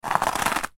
Footstep In Icy Snow ringtone free download
Sound Effects